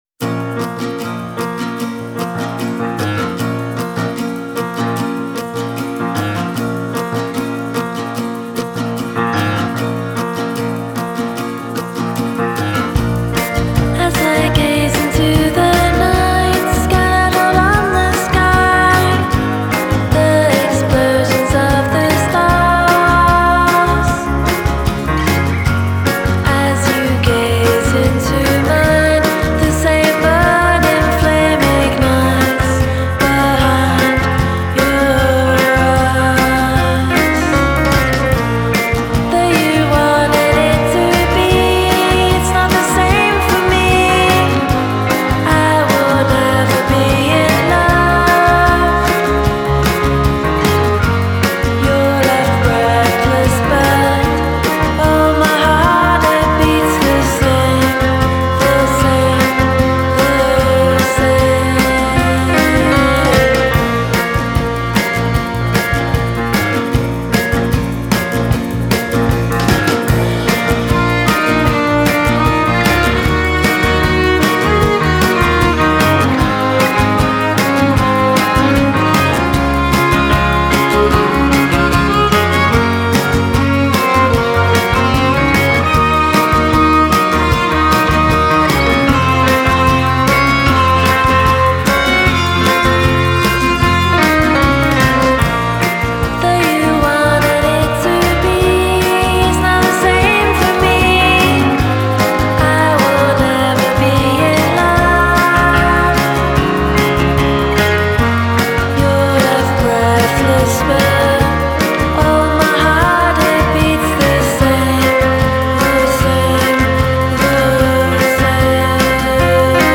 Genre: Indie Pop / Twee